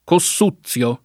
[ ko SS2ZZL o ]